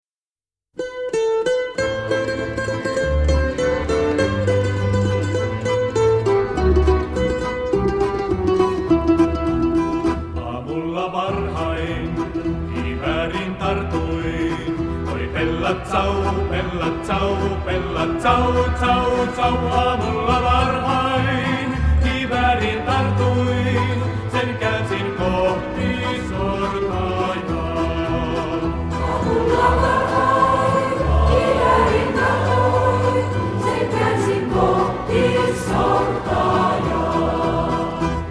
Italial. partisaanilaulu
kontrabasso
mandoliini
kitara